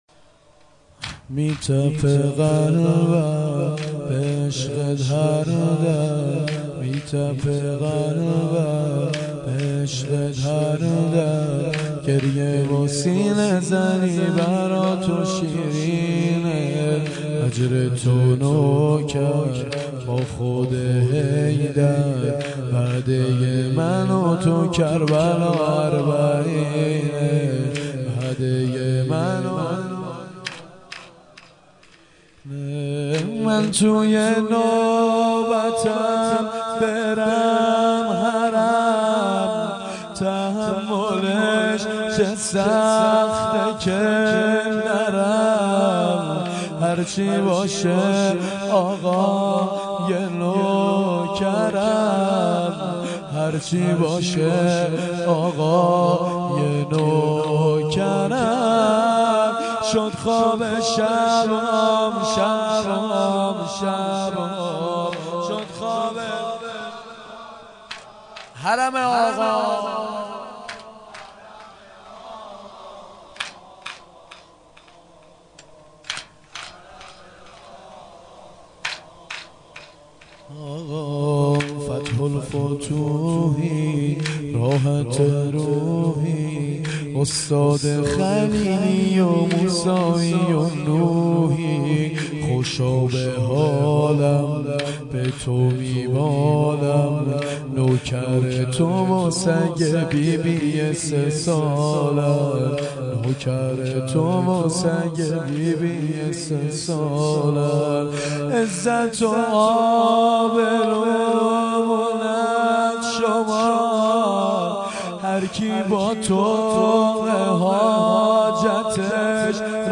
شب پنجم محرم ۱۴۴۱